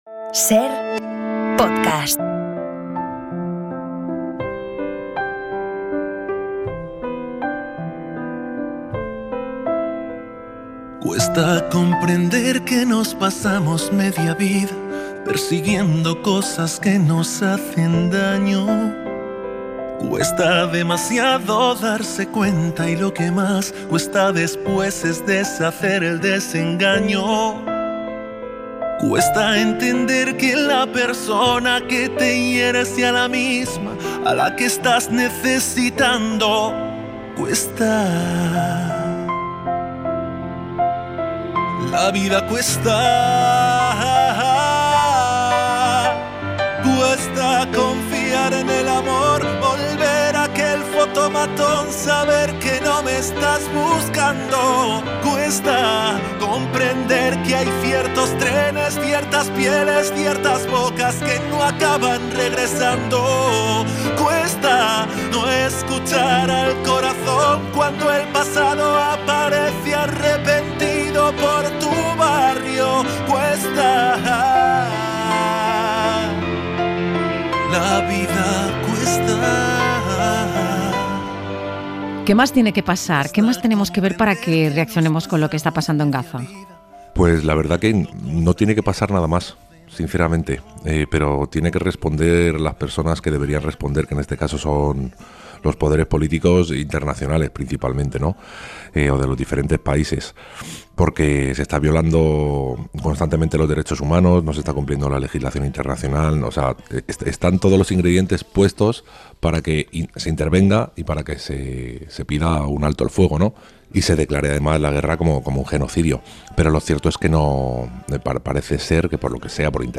La entrevista | Marwan